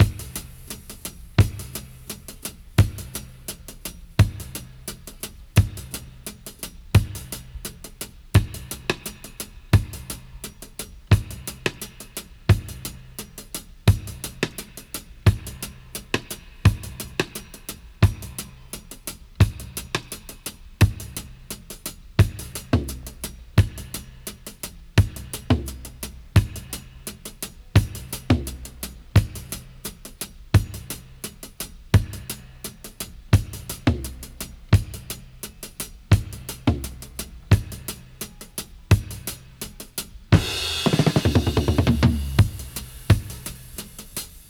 85-FX-02.wav